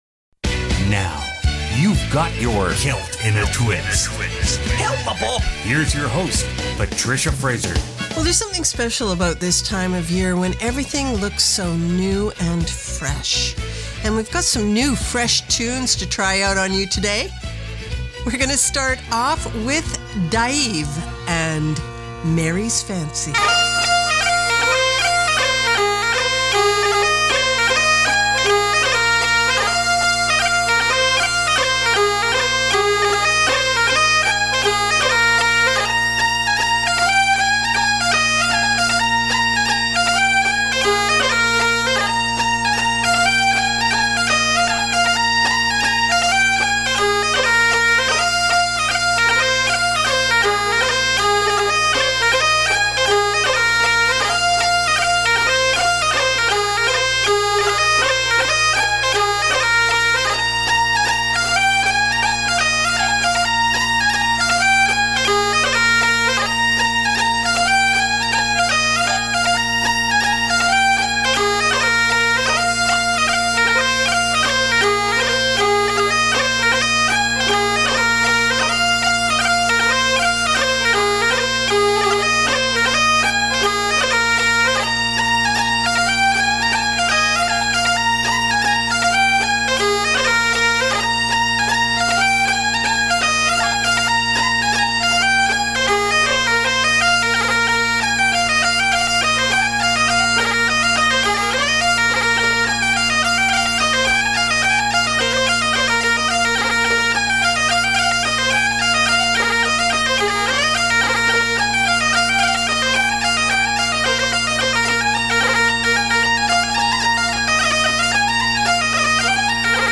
Canada's Contemporary Celtic Hour